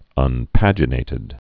un·pag·i·nat·ed
(ŭn-păjə-nātĭd)